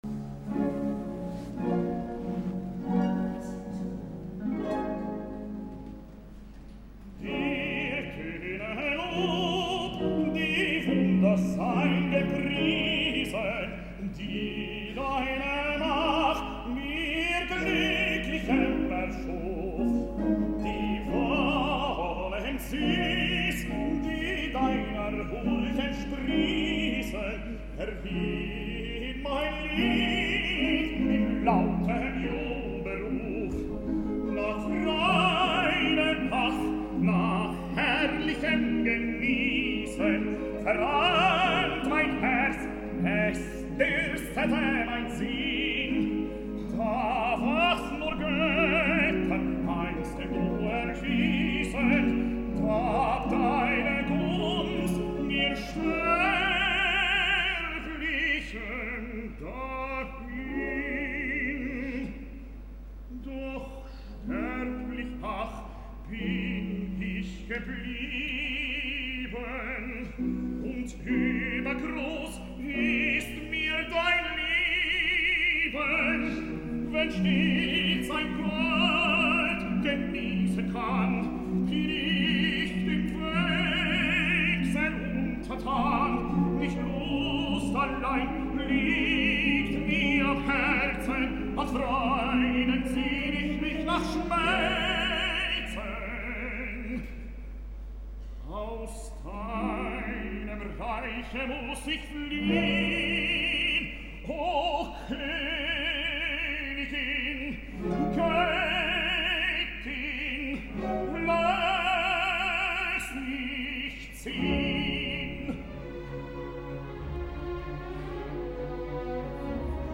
BAYREUTH 2012: TANNHÄUSER
Tannhäuser a Bayreuth.
La versió del genial director és enlluernadora i definitiva.